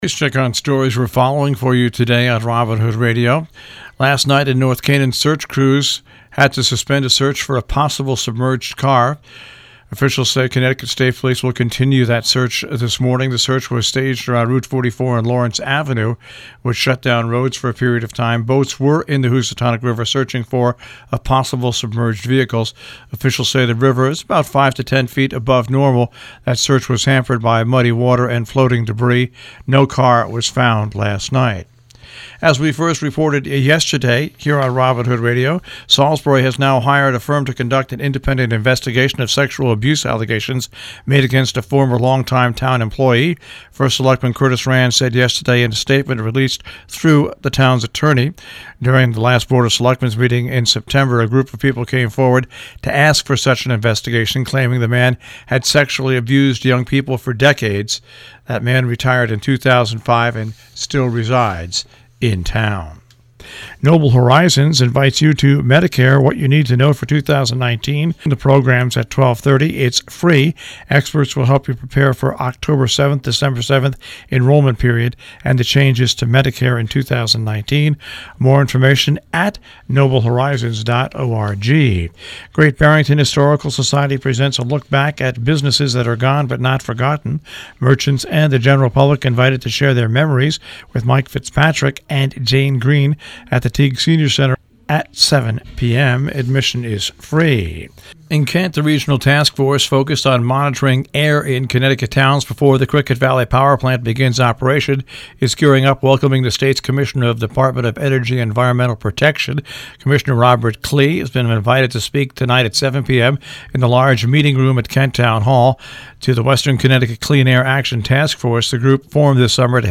WHDD Breakfast Club News